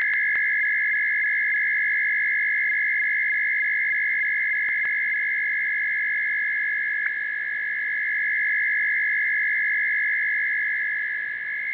ARQ-E e ARQ-N / ARQ 1000 DUPLEX